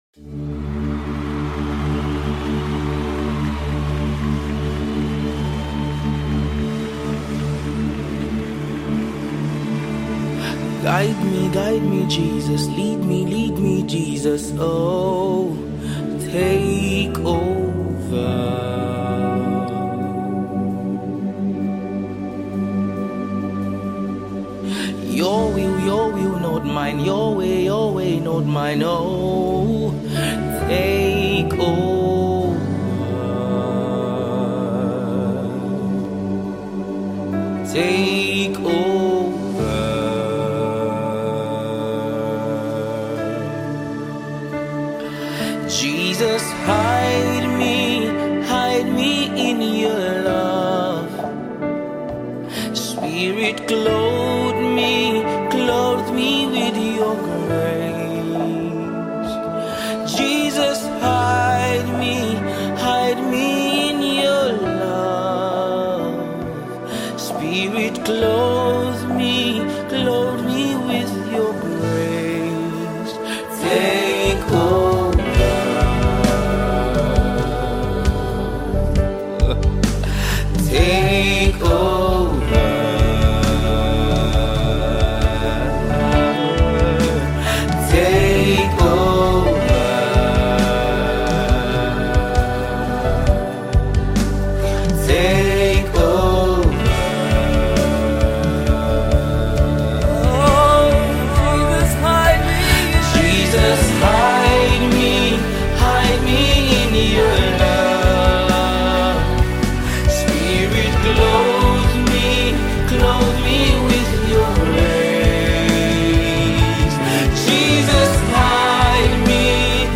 March 18, 2025 Publisher 01 Gospel 0